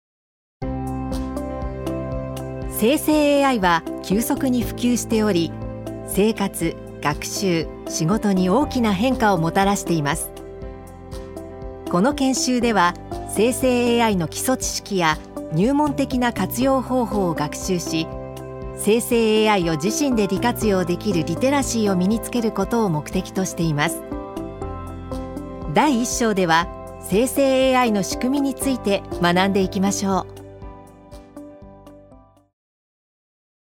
女性タレント
音声サンプル
ナレーション１